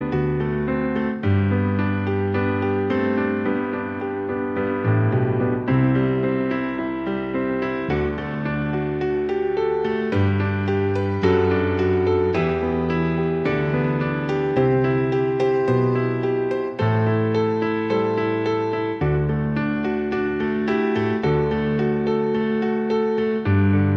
Irish Backing Tracks for St Patrick's Day